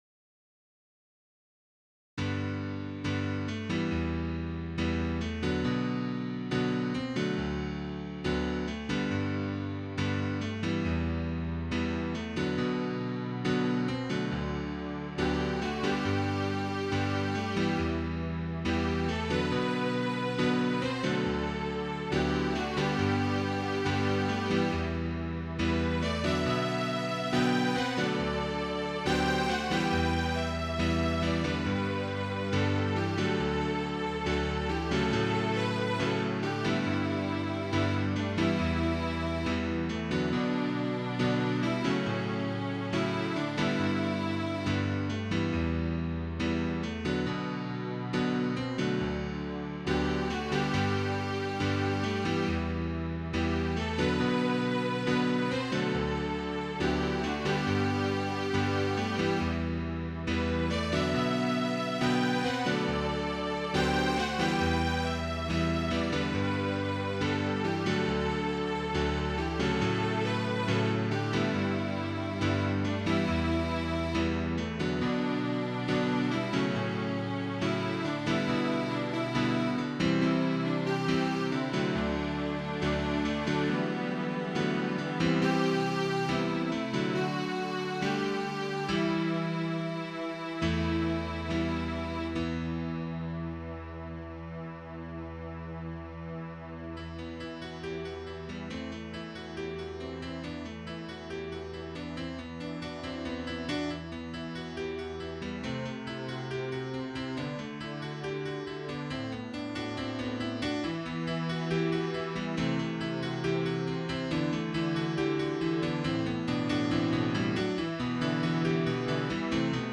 Title The River's Flow Opus # 20 Year 2001 Duration 00:03:26 Self-Rating 3 Description This is a good example of how I seem to compose "pop classical." mp3 download wav download Files: mp3 wav Tags: Quartet, Piano, Strings Plays: 2604 Likes: 0